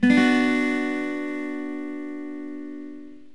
SimpleBm.mp3